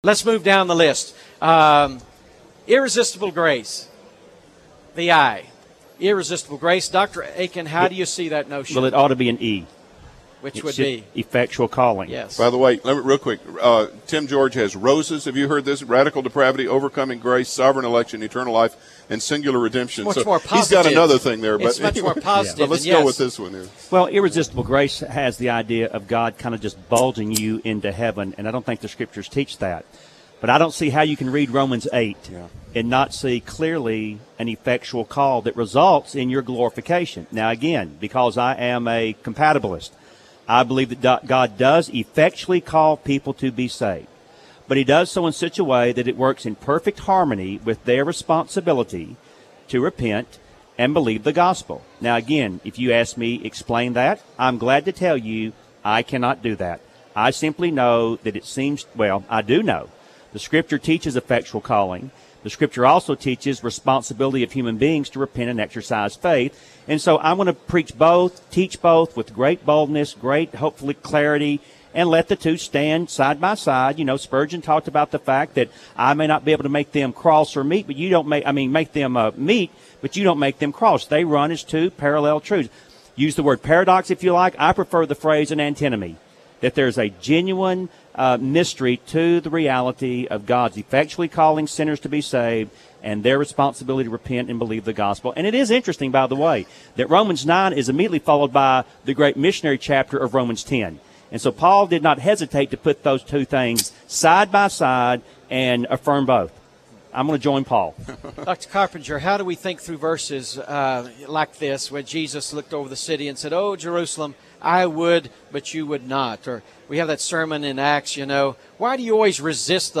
Debate on Calvinism